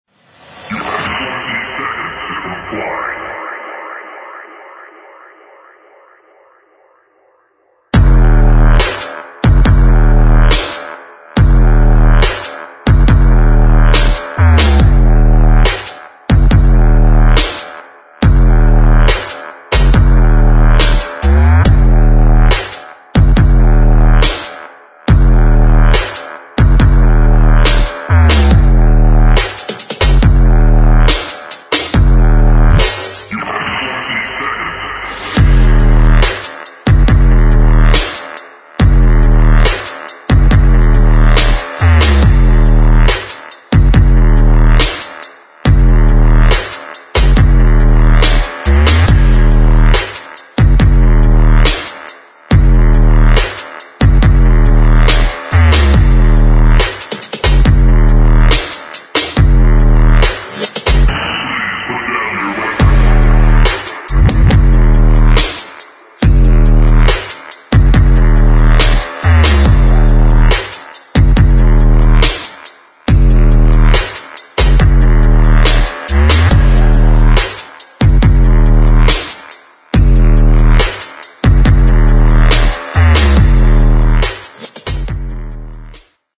[ DUBSTEP / GRIME ]